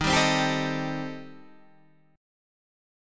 Eb6b5 Chord